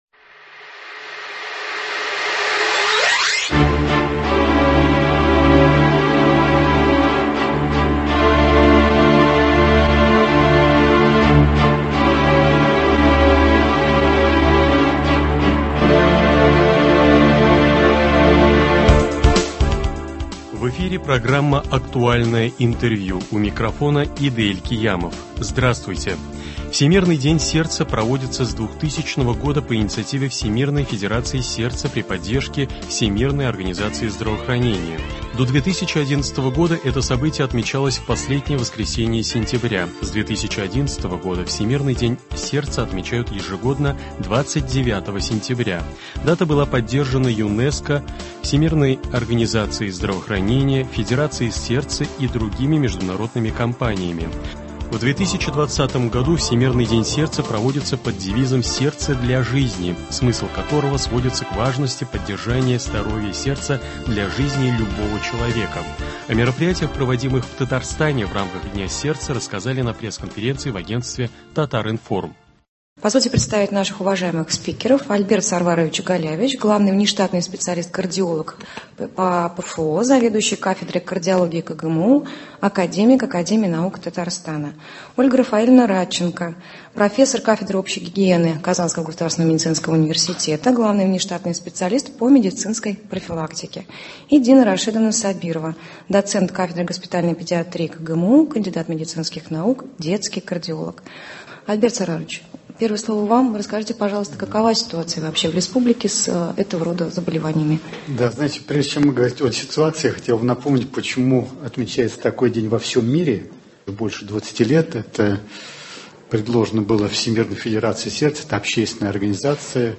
Актуальное интервью (29.09.2021)